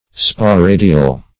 Sporadial \Spo*ra"di*al\ (sp[-o]*r[=a]"d[i^]*al)